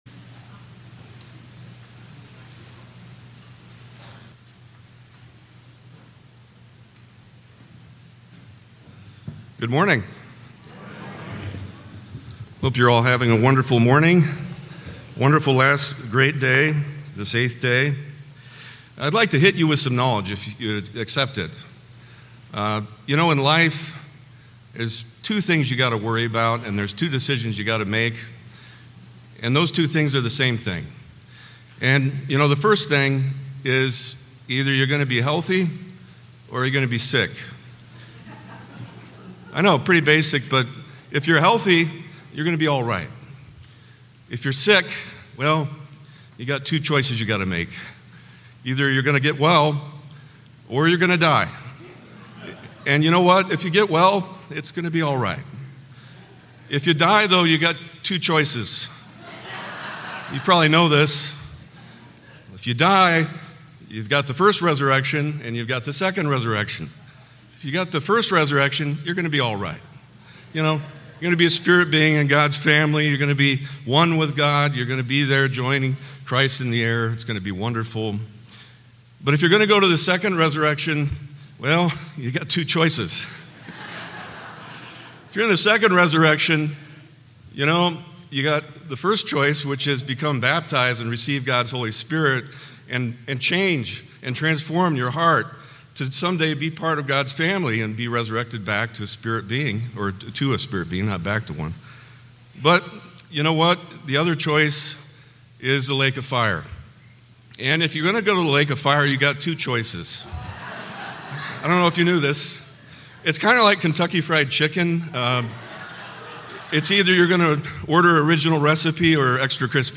Given in Temecula, California